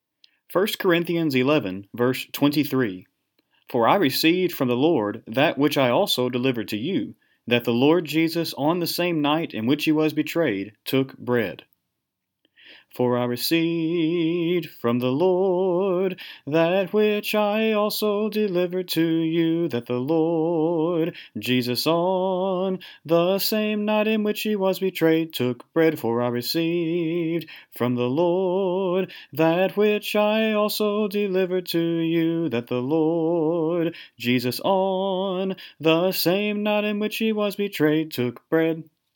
To encourage young people to memorize the Bible, we like to make simple, fun, and (sometimes) silly tunes to aid in their memorization.